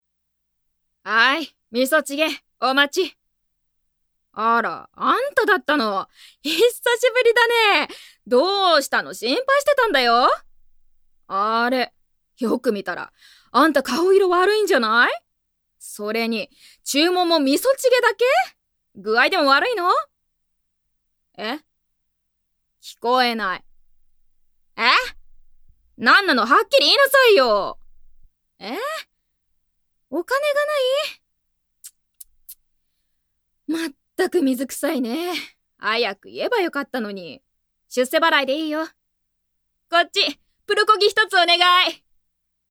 ◆おばあちゃん◆